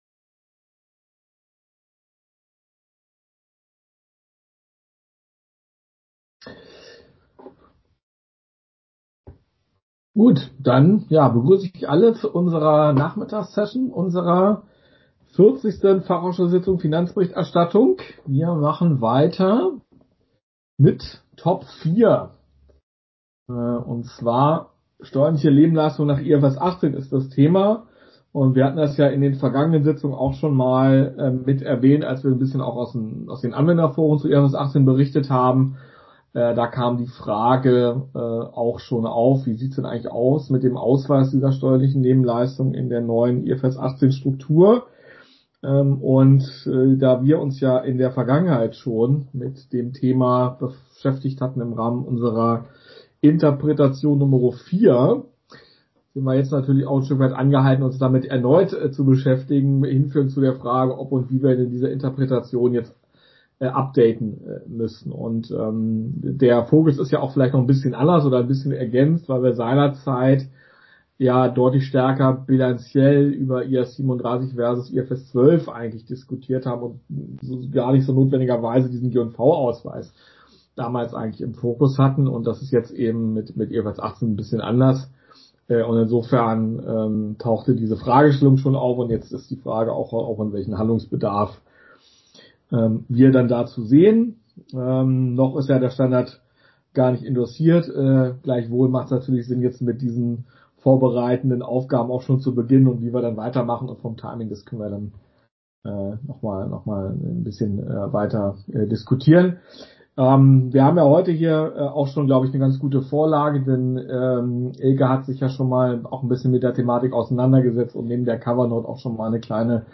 40. Sitzung FA Finanzberichterstattung • DRSC Website